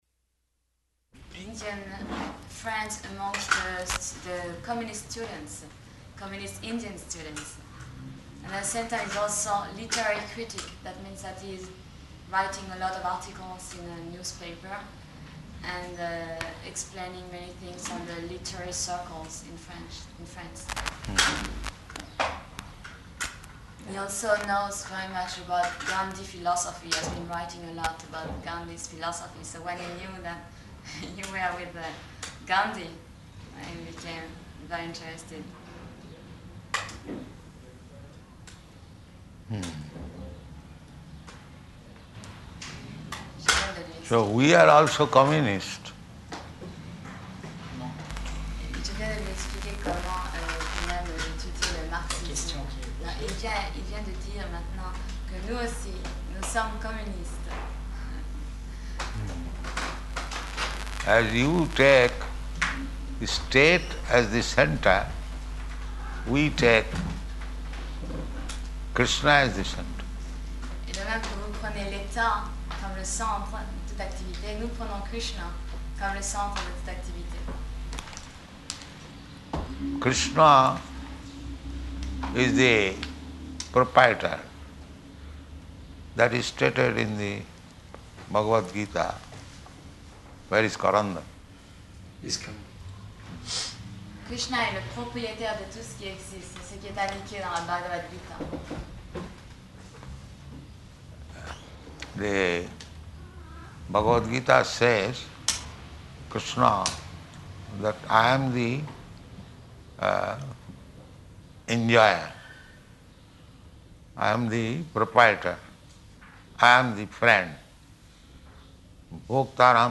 Type: Conversation
Location: Paris